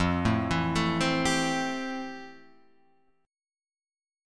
F Major 9th